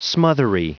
Prononciation du mot smothery en anglais (fichier audio)
Prononciation du mot : smothery